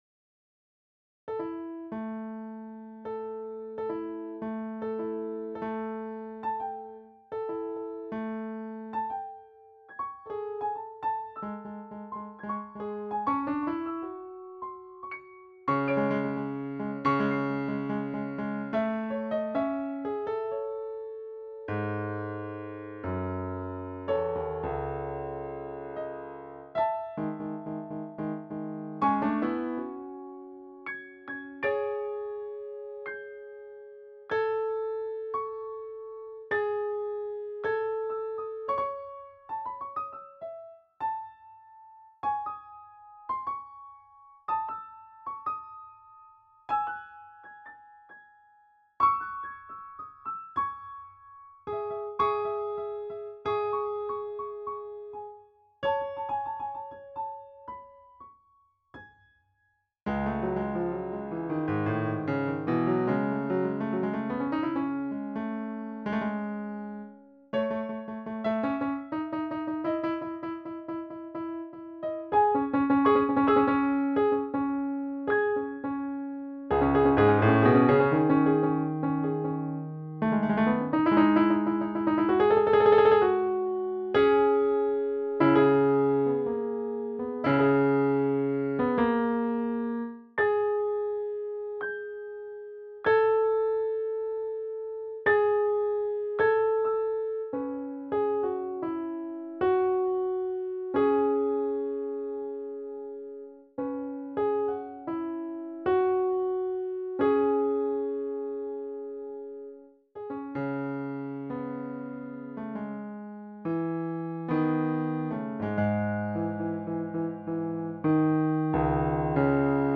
Posted in Classical, Piano Pieces Comments Off on